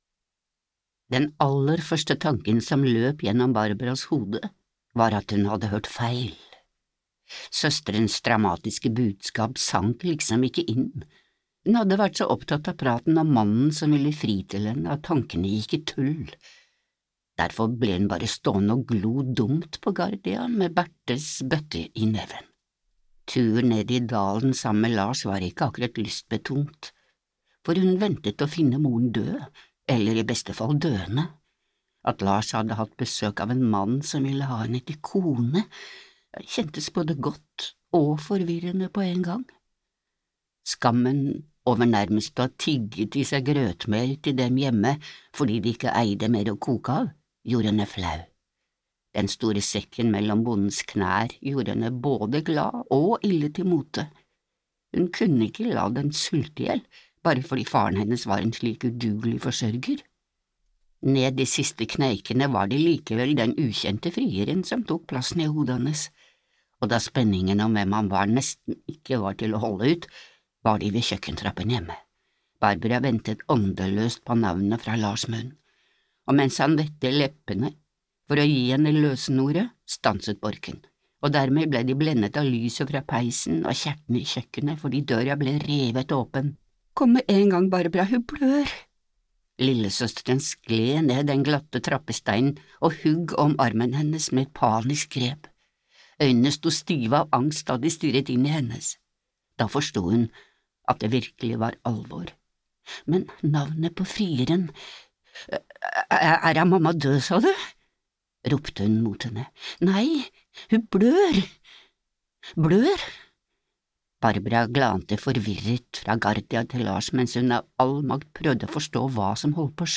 Utdraget er hentet fra lydbokbokutgaven